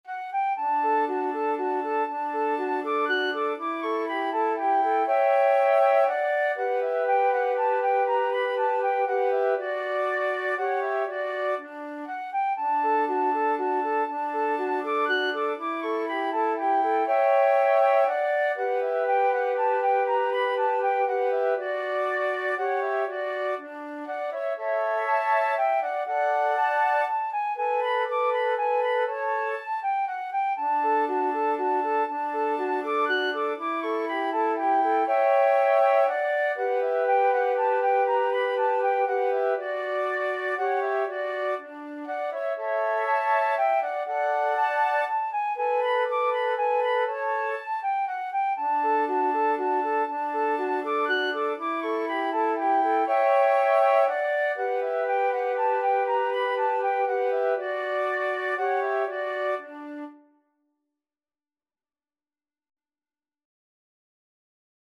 Flute 1Flute 2Flute 3
3/4 (View more 3/4 Music)
Classical (View more Classical Flute Trio Music)